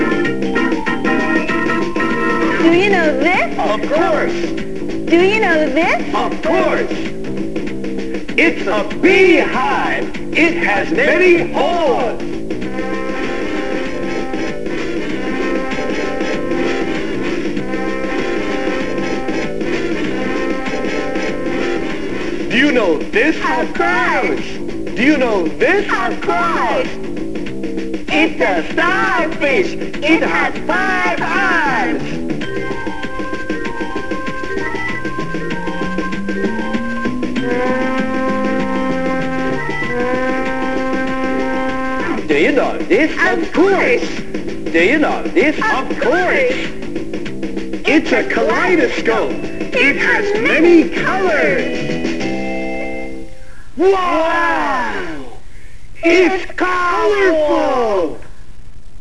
歌のコーナー